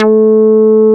P MOOG A4MF.wav